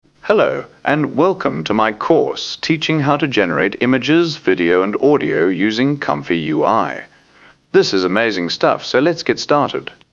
Custom TTS node that clones voice from a reference audio and speaks entered text.
Voice Clone
voice-clone_00001_.mp3